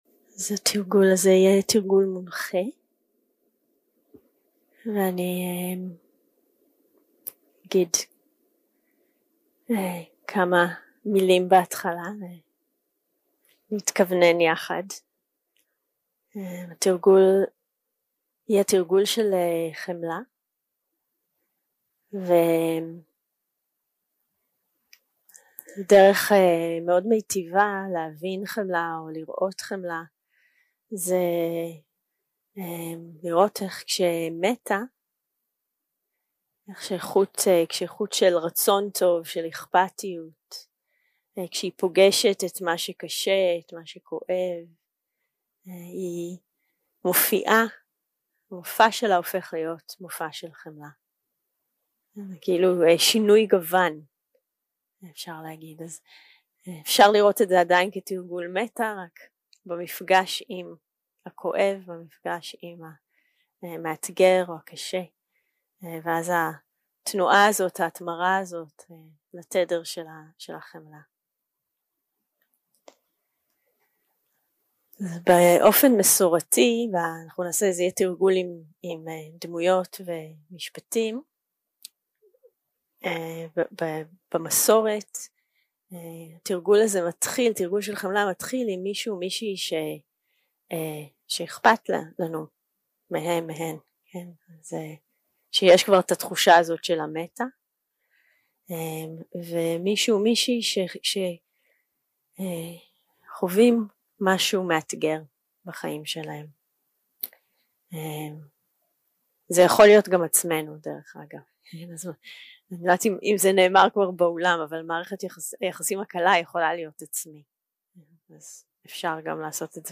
יום 5 – הקלטה 12 – צהריים – מדיטציה מונחית - חמלה
Dharma type: Guided meditation שפת ההקלטה